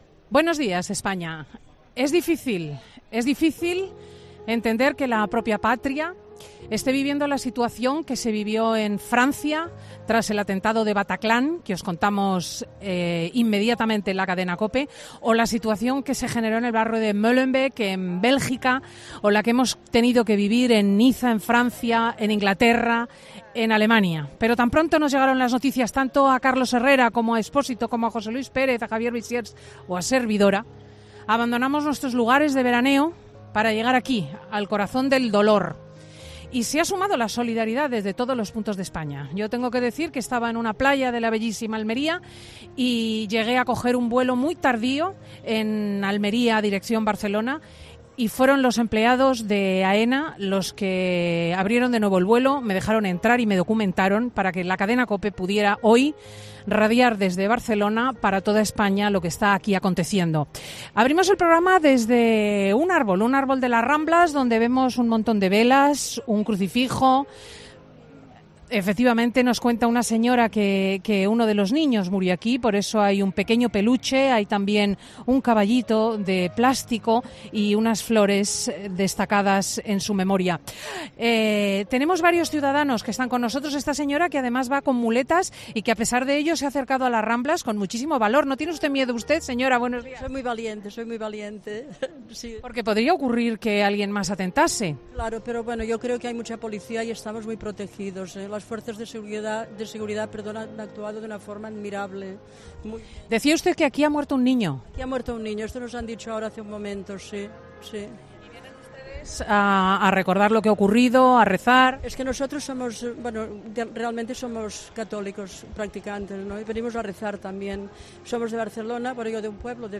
Cristina López Schlichting emite 'Fin de semana' desde Barcelona